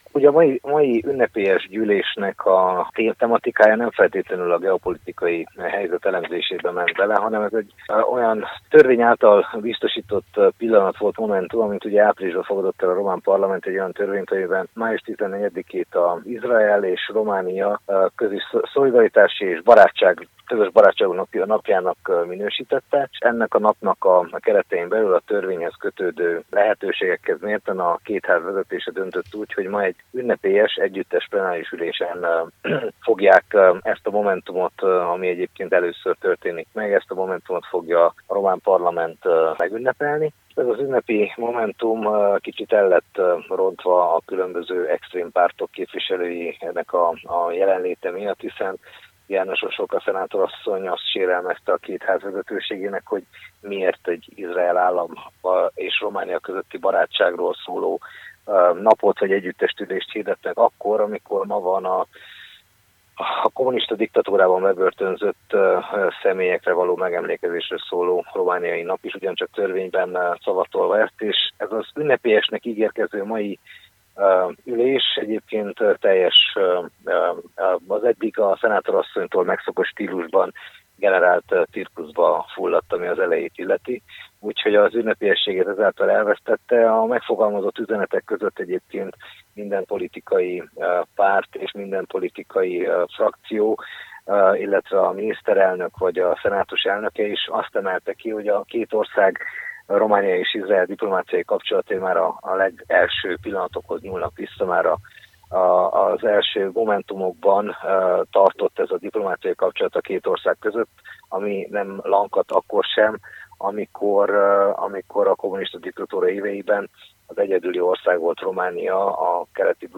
A Románia és Izrael közötti szolidaritás és barátság napja alkalmából tartott ünnepi parlamenti ülésen az RMDSZ nevében Nagy Szabolcs RMDSZ-es képviselő szólalt fel.